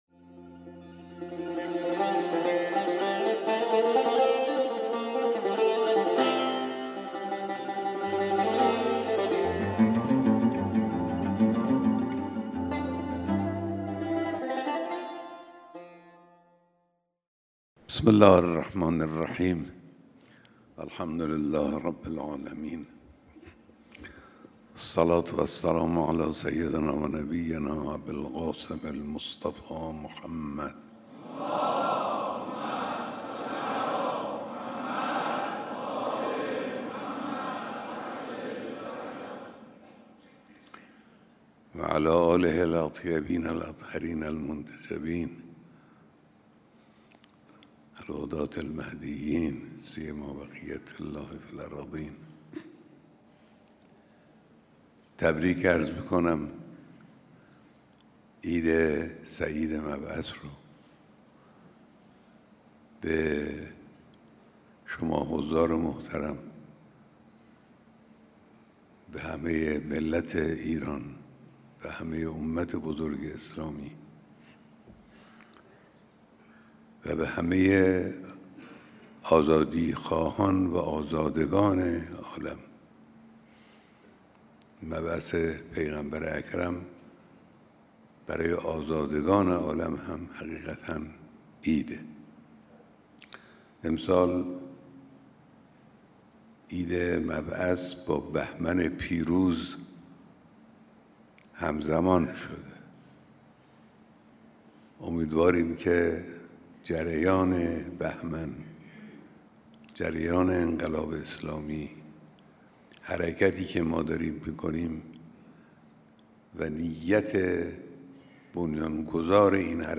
بیانات در دیدار جمعی از مسئولان نظام، سفرای کشورهای اسلامی و قشرهای مختلف مردم